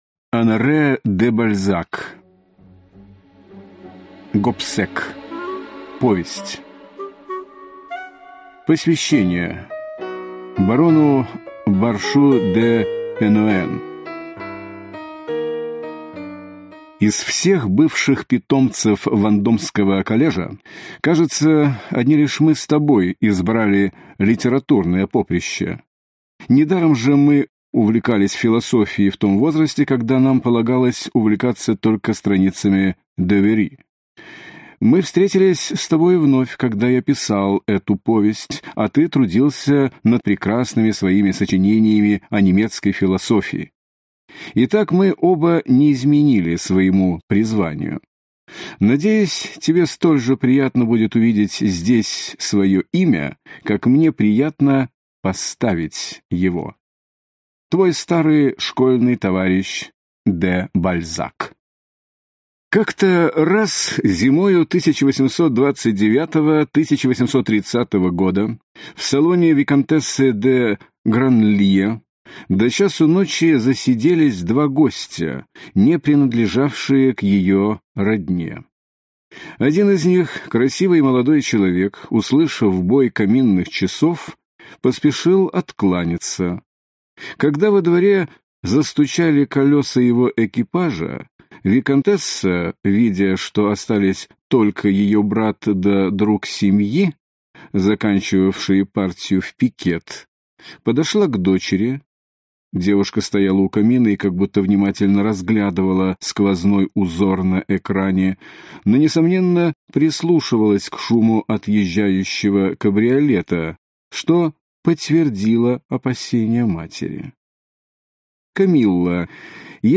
Аудиокнига Гобсек. Отец Горио (сборник) | Библиотека аудиокниг
Прослушать и бесплатно скачать фрагмент аудиокниги